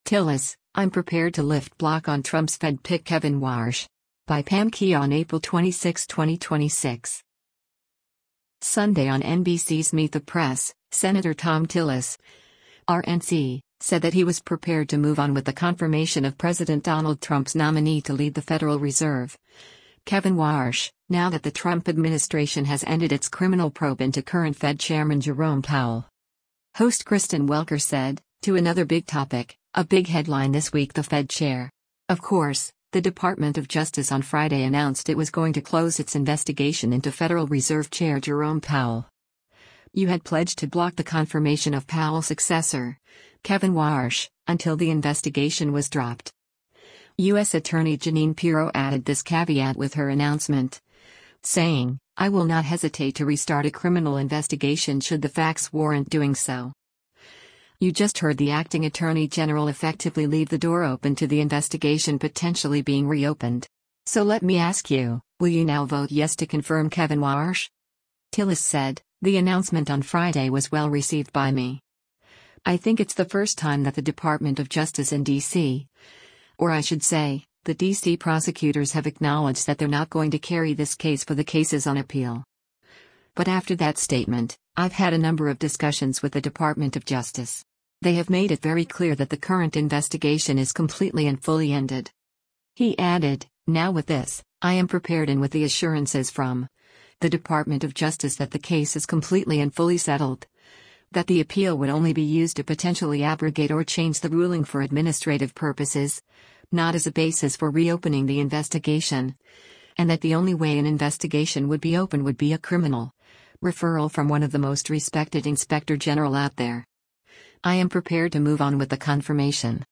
Sunday on NBC’s “Meet the Press,” Sen. Thom Tillis (R-NC) said that he was “prepared to move on with the confirmation” of President Donald Trump’s nominee to lead the Federal Reserve, Kevin Warsh, now that the Trump administration has ended its criminal probe into current Fed Chairman Jerome Powell.